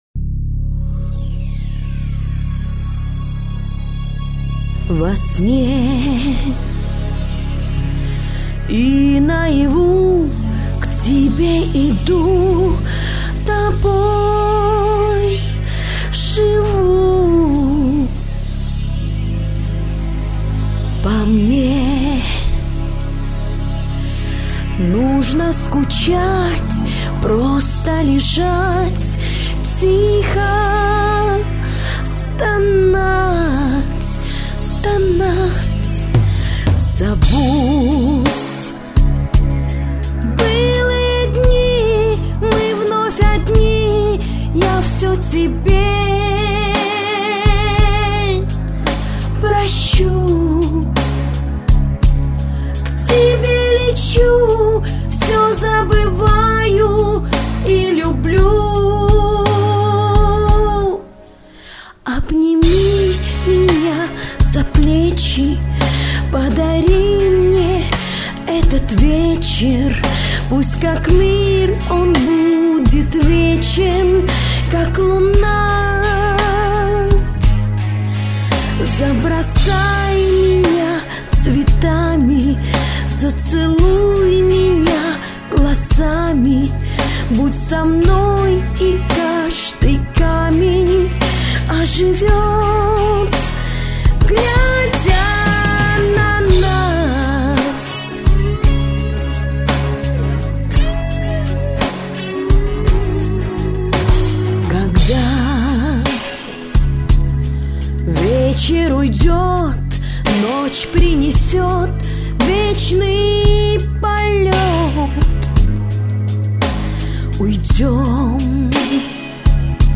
По высшему разряду мадамочка спела!!!